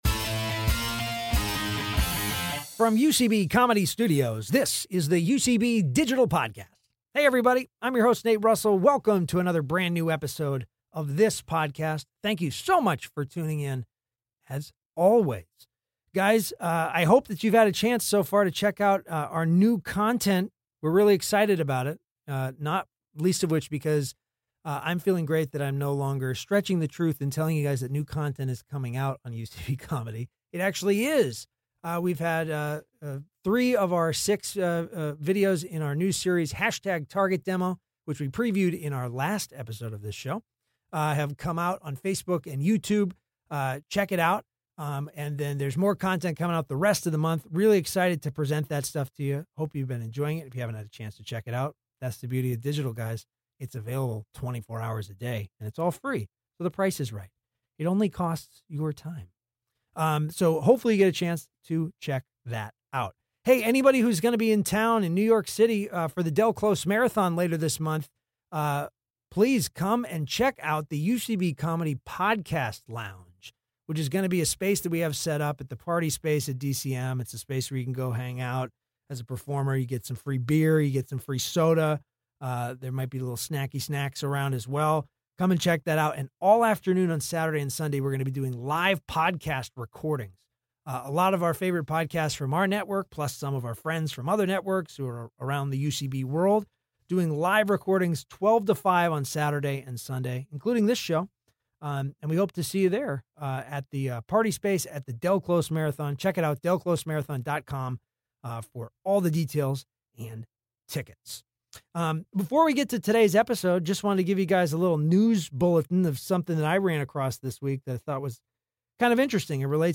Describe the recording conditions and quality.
Recorded at UCB Comedy Studios East in New York City.